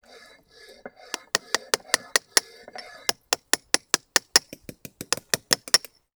TOOL_Chisel_Sequence_02_mono.wav